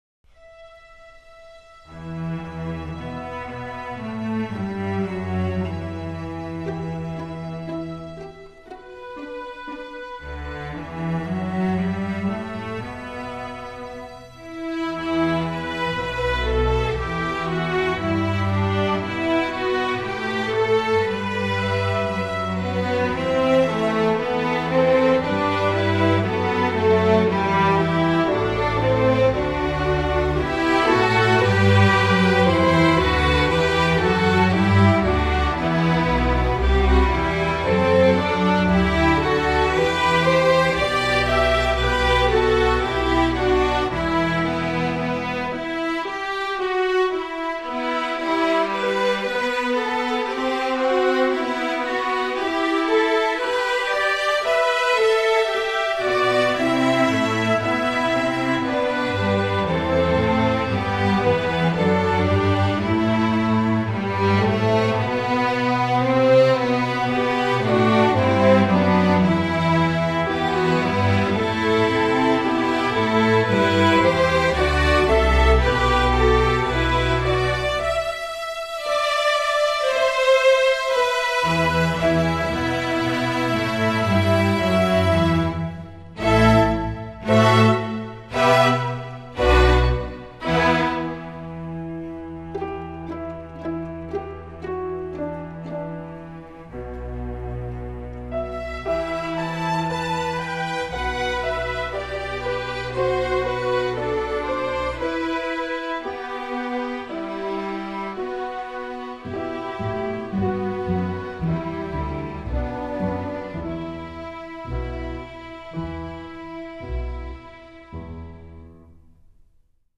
English Carol
String Orchestra